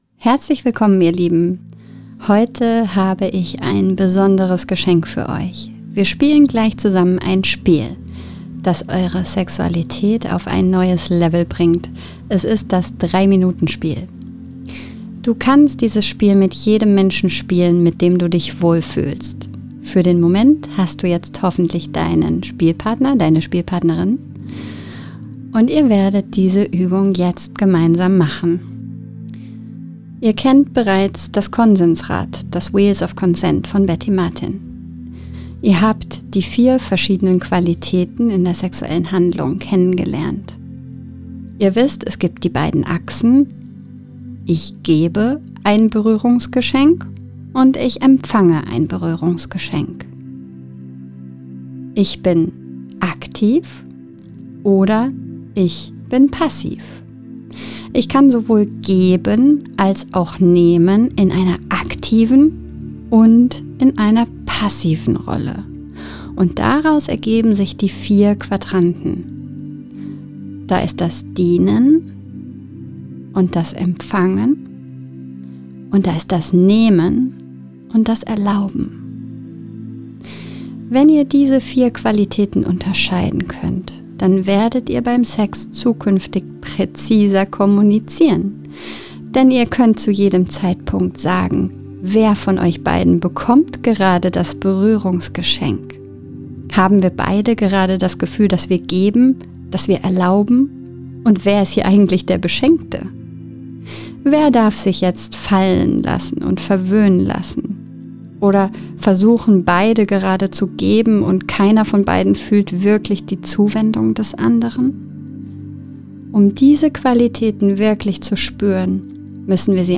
Das-3-Minuten-Spiel-MEDITATION.mp3